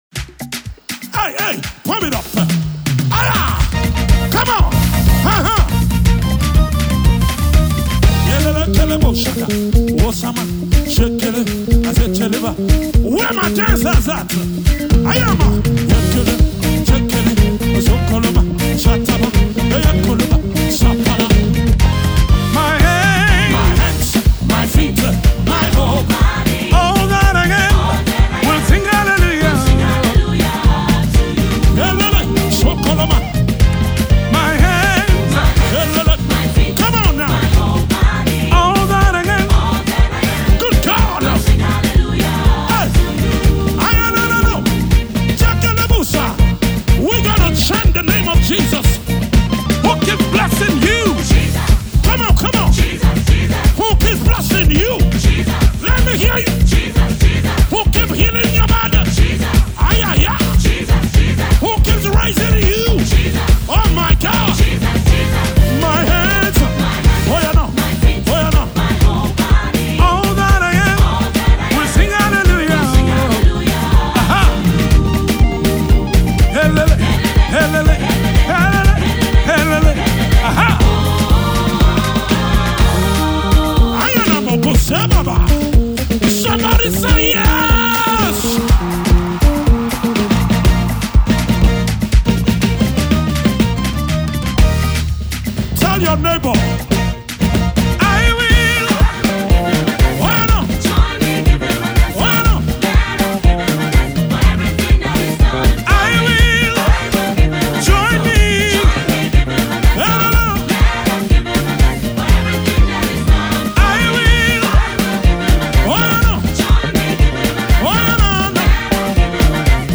catchy praise tune